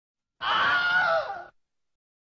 Wilhelm Scream